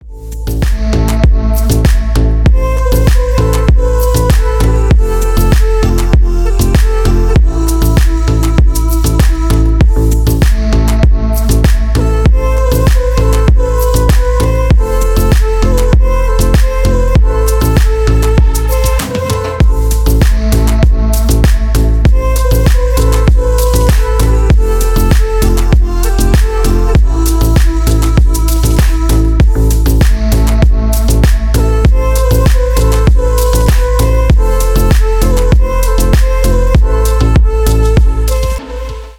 Поп Музыка
без слов